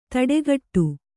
♪ taḍegaṭṭu